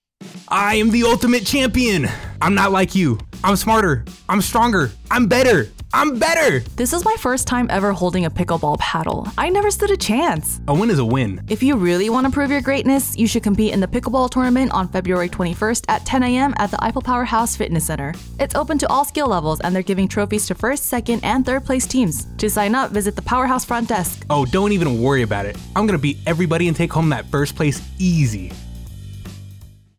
This is a 30-second radio spot highlighting the pickleball tournament at Spangdahlem Air Base, Germany, Jan. 29, 2026. The event is a friendly competition played by all skill levels to boost morale across the 52nd Fighter Wing.